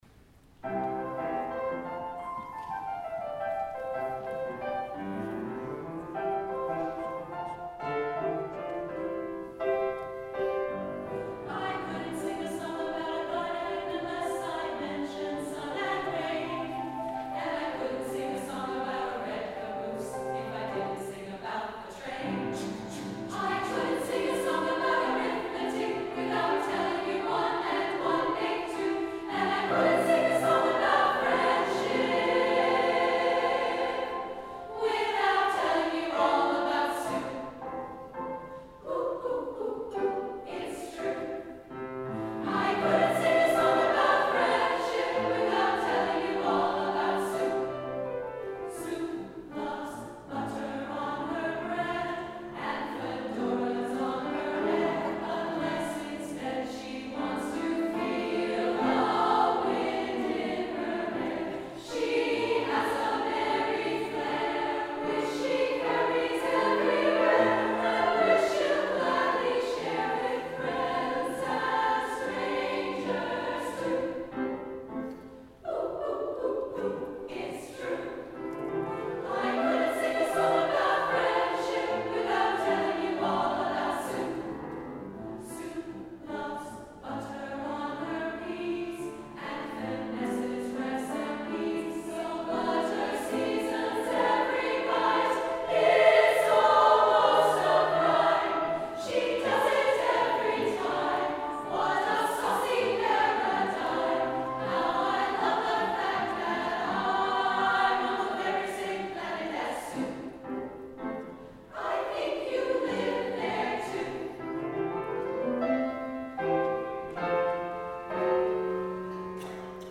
SSA, piano